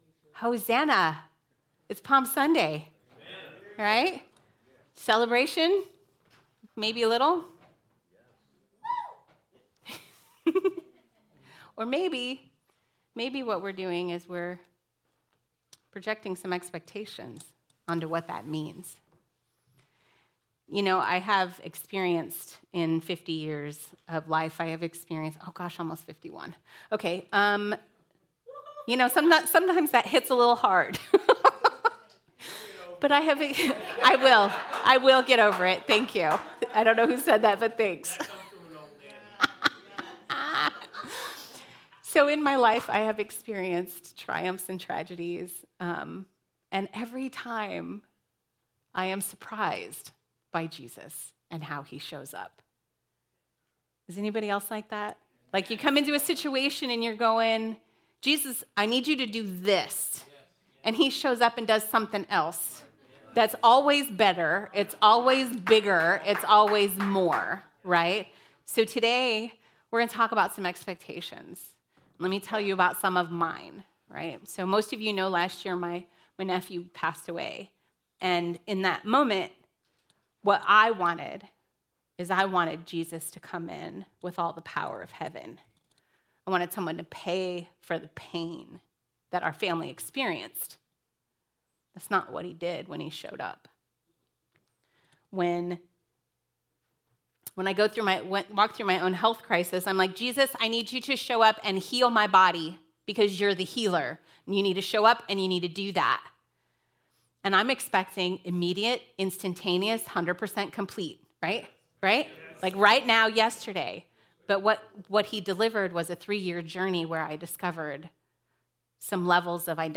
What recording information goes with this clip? Palm Sunday Service - April 2nd, 2023